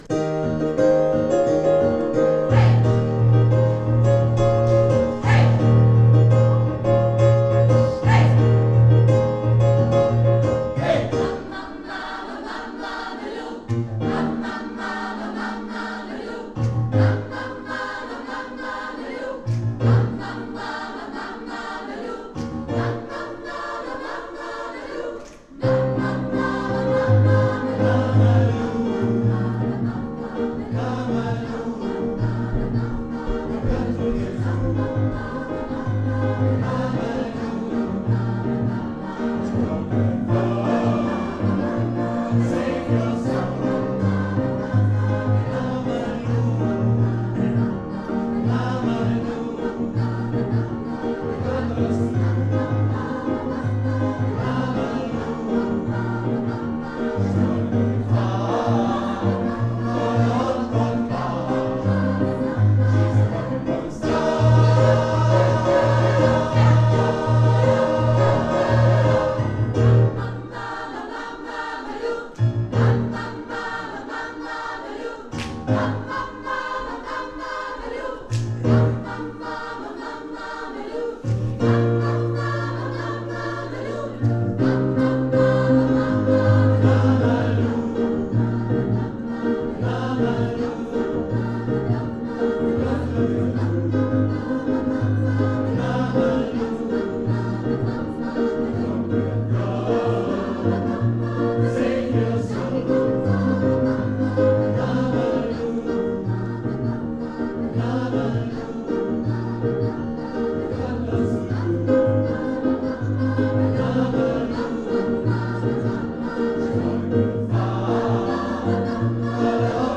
09 - Konzertaufnahmen - ChorArt zwanzigelf - Page 5
Kathy Kelly mit ChorArt zwanzigelf 17.03.2019 – Mama Loo